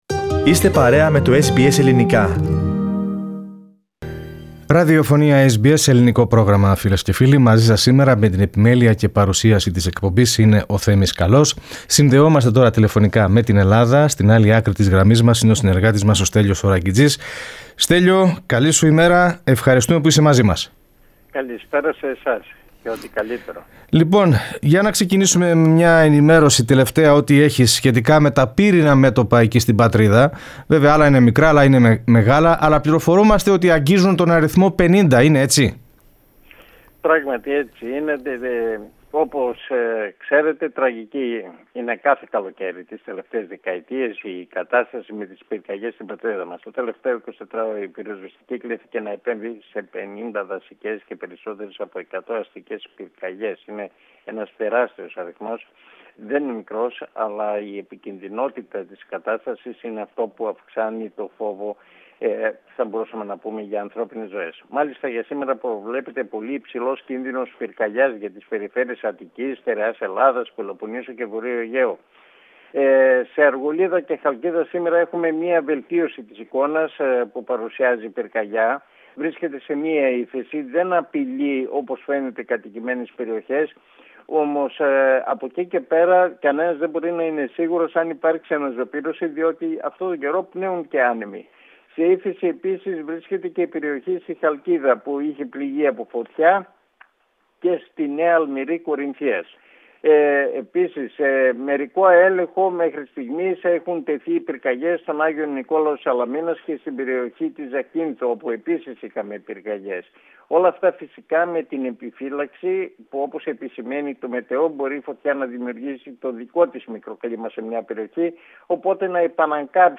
Πατήστε PLAY πάνω στην εικόνα για να ακούσετε την ανταπόκριση του SBS Greek/SBS Ελληνικά .